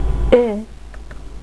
Ponctuez le discours de votre interlocuteur par des "Ee" (à prononcer "éé") ou des "Hai", à chaque pause convenable.